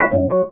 Key-organ-03_000.wav